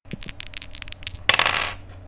diceRoll.ogg